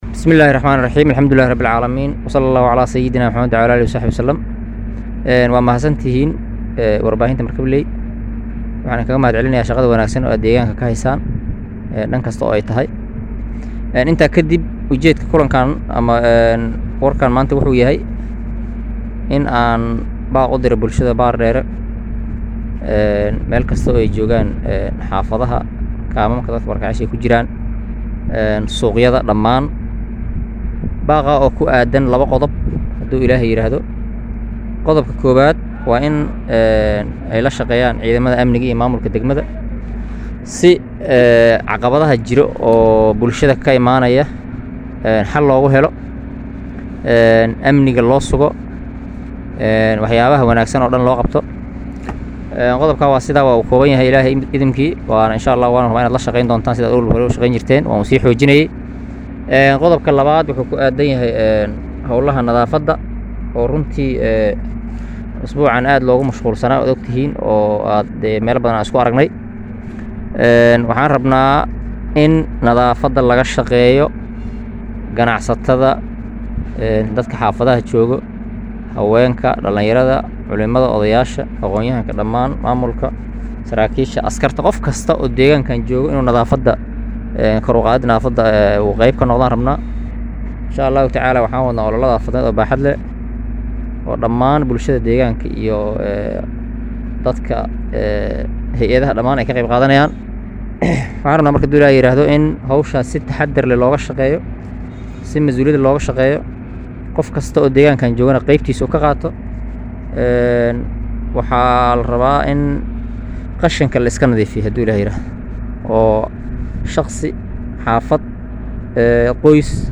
Maxamed Weli Yusuf Qorax oo ah Gudoomiyaha Maamulka degmada Baardheere oo la hadlayey Warbaahinta Markabley ayaa ugu Baaqey Qeybaha kale duwan ee Bulshada ku dhaqan Baardheere in ay ka shaqeeyaan ka qeyb qaadashada Horomarinta Nadaafada degmada iyo la shaqeynta ciidamada Amniga deegaanka.
WAREYSI-GUDOOMIYAHA-BAARDHEERE-1.mp3